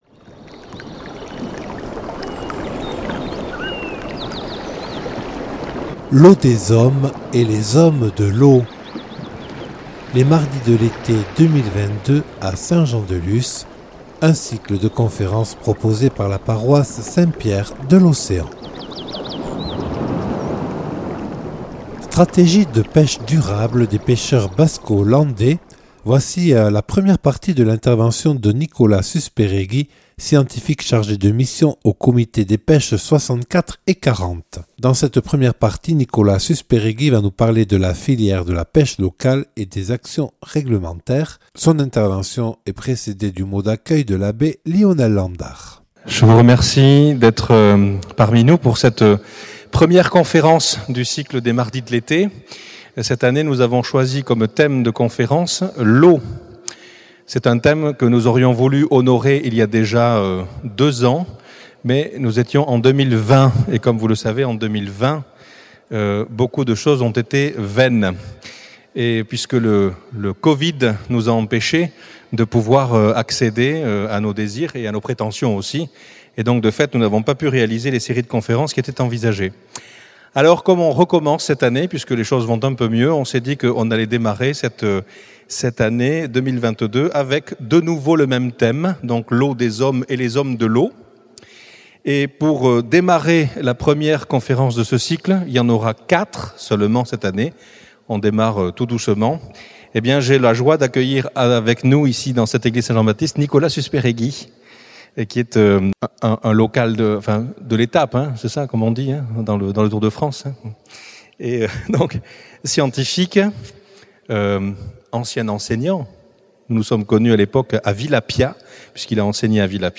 Première partie de la conférence